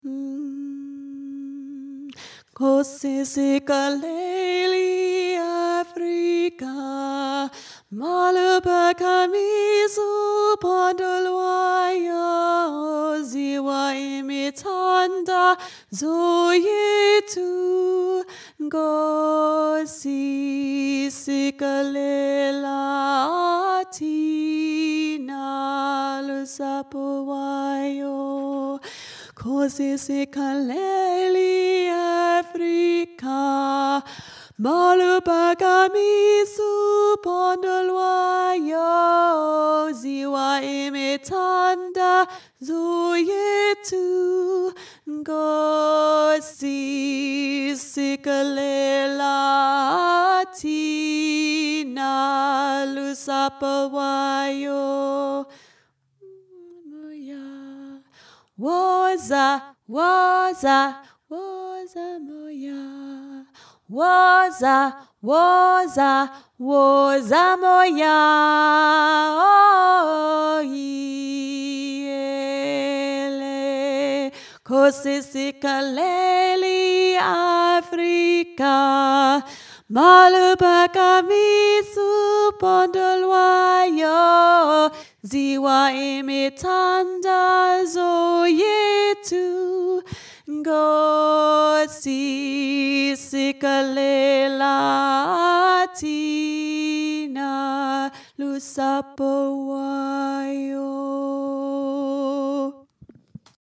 Nkosi Alto